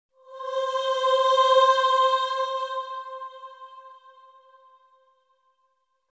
Choir Ahh Sound Button | Sound Effect Pro